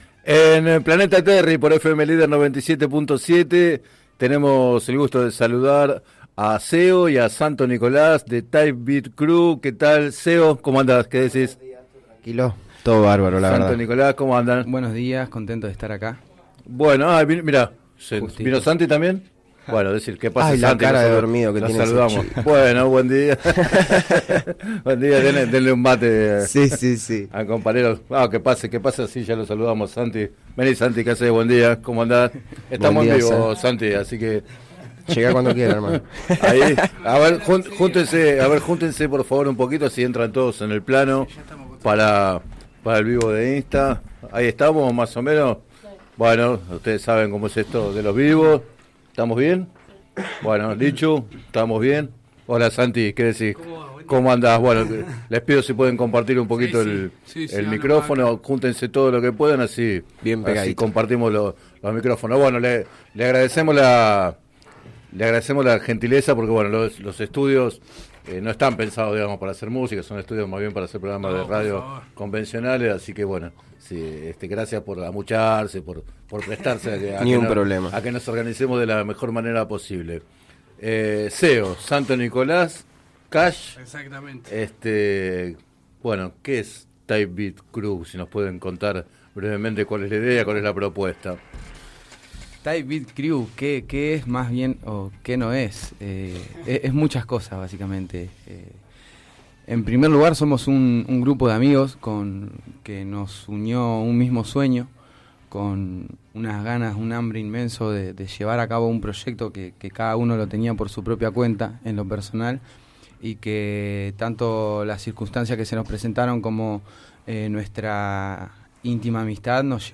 música rap